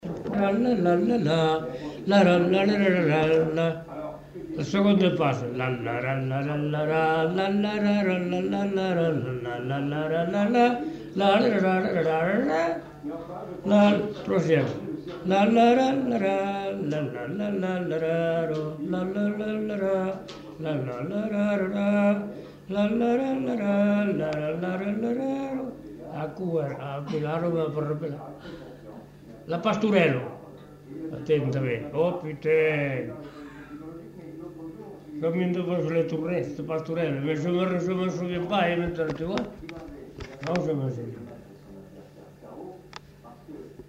Aire culturelle : Savès
Lieu : Pavie
Genre : chant
Effectif : 1
Type de voix : voix d'homme
Production du son : fredonné
Danse : quadrille